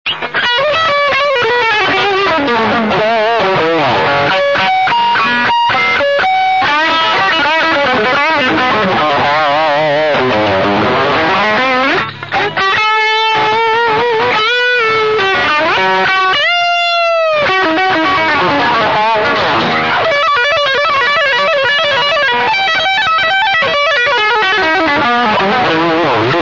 Il suono è indescrivibile, ogni nota richiama una cascata di coriandoli luccicanti, praticamente un diluvio di armoniche. Ogni bending è una coltellata, secondo me è il massimo del suono "bello".